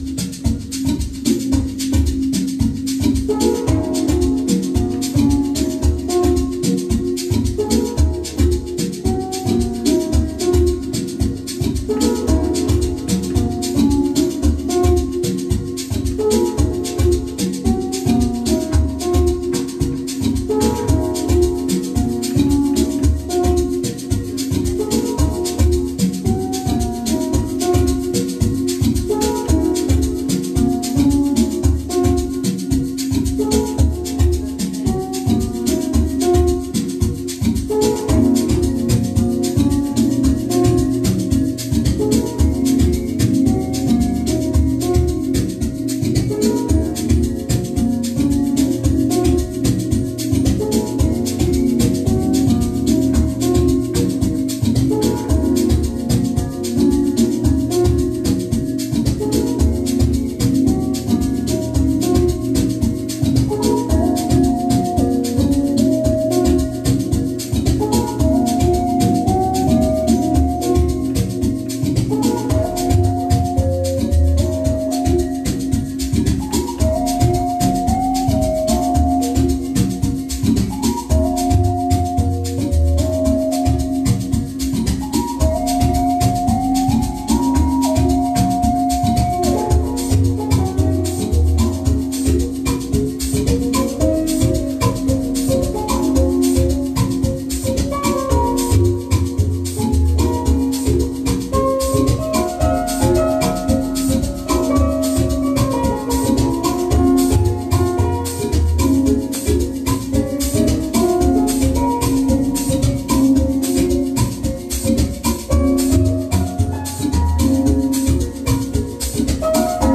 Amapiano Live Sessions mix
mixtape
feel good mix